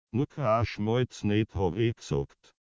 "Nur kein Schmalz nicht habe ich gesagt" auf Wienerisch:
schmalz_dialekt_66038.mp3